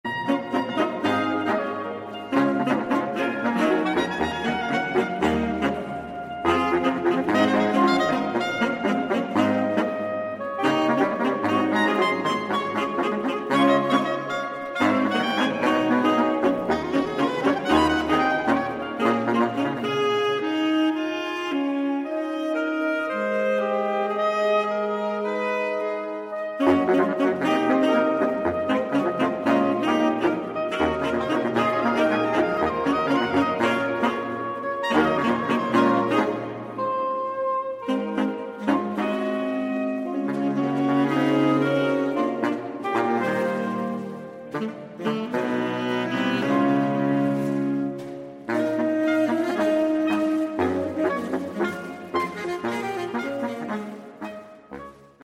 – arrangiert für neun Saxophone solo plus Pauken / Perkussion
(Alternativ: Arrangement für acht Saxophone, Pauken, Chor (SAATB))
Magnifisax verbindet Barock mit Jazz, die Strenge Bachscher Musik mit der Freiheit persönlichen Ausdrucks.
Hörbeispiele MagnifiSAX mit Chören der Marienkantorei Lemgo (Live 8.11.2019):